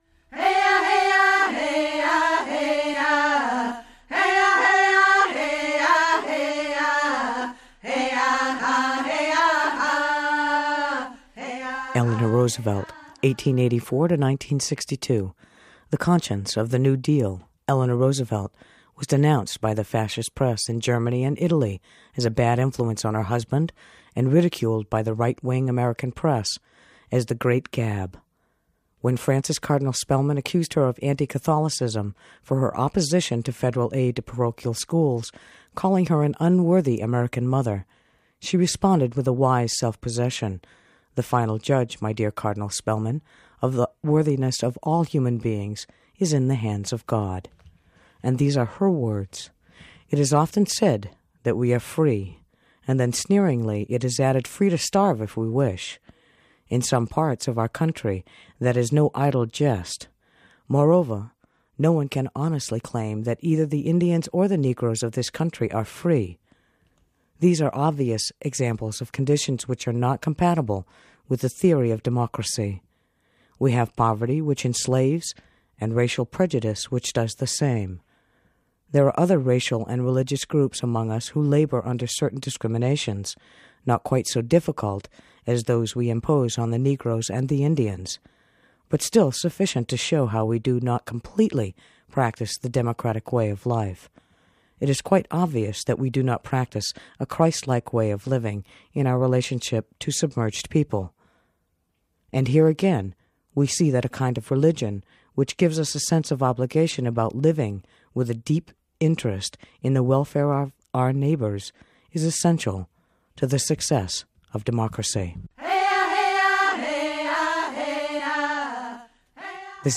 a piece read on KMUD